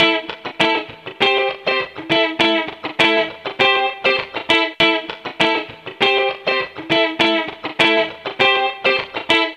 Sons et loops gratuits de guitares rythmiques 100bpm
Guitare rythmique 44